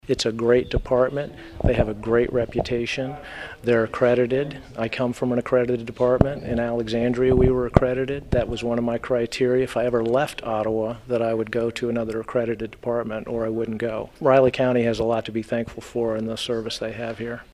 Butler says he’s thrilled to be coming to Manhattan.